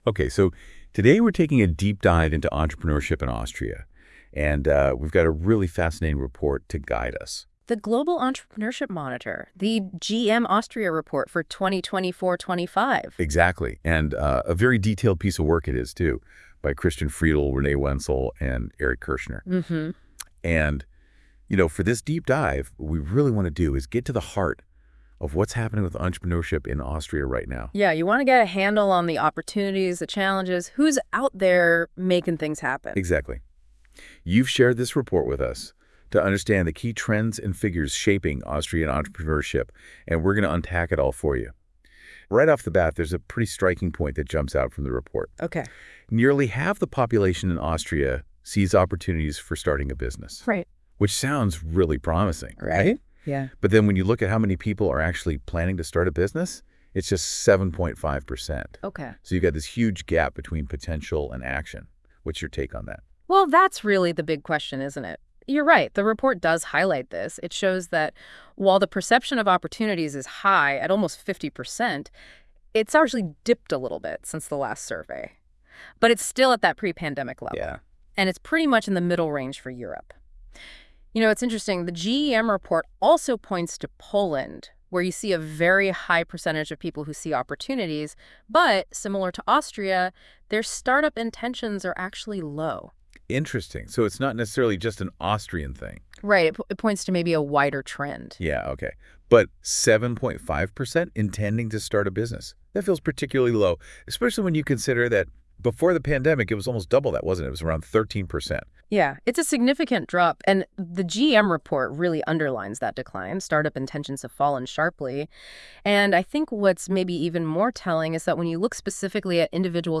KI Podcast zu den Schlüsselergebnissen
In einer neuen, vollautomatisch generierten Podcast-Episode fassen wir die zentralen Erkenntnisse, Zahlen und Trends des aktuellen Global Entrepreneurship Monitor (GEM) für Österreich zusammen.